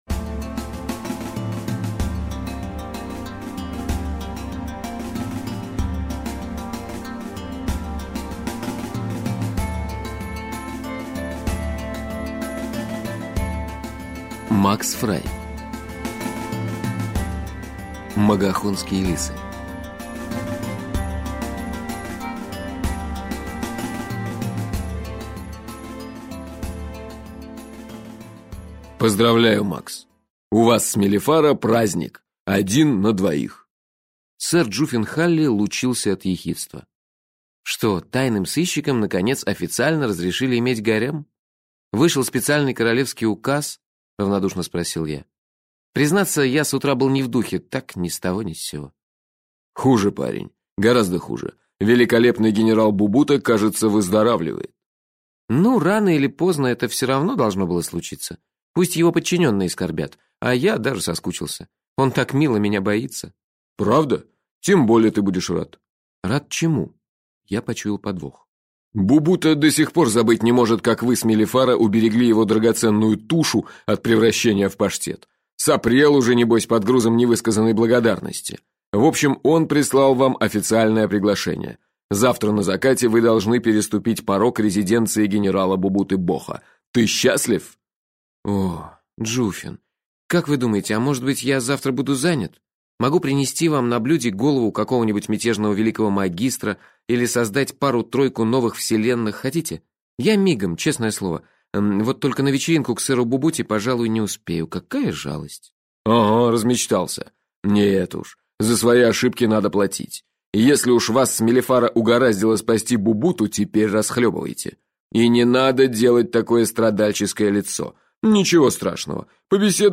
Аудиокнига Волонтеры вечности (сборник) | Библиотека аудиокниг